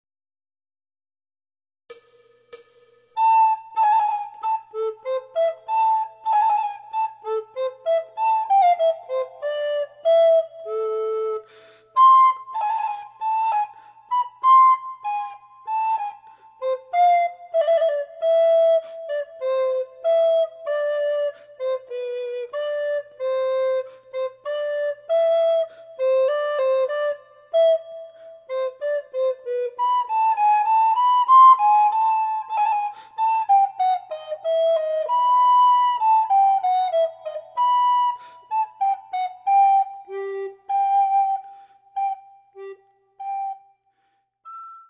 アルトリコーダーデュオソナタ
演奏例は、ほとんどがアマチュアのリコーダー奏者によるものです。
第１リコーダー用マイナスワン